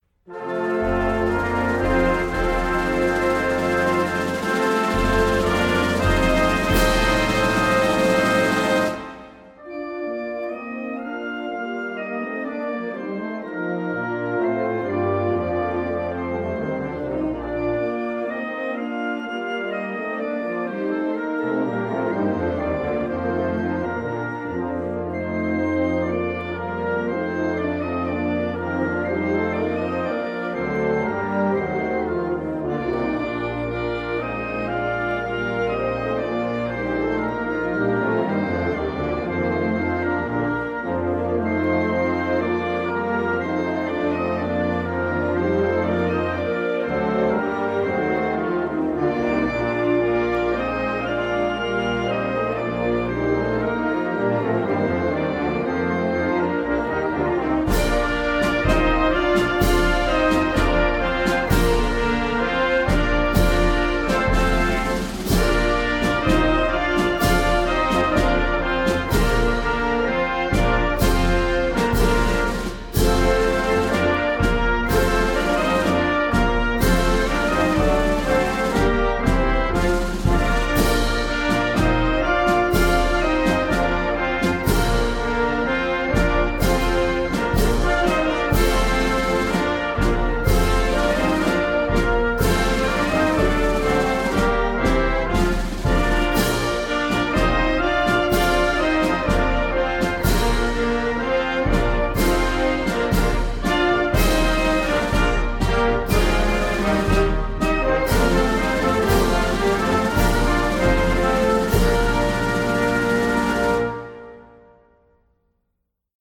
Anthem_of_Europe_(US_Navy_instrumental_long_version).mp3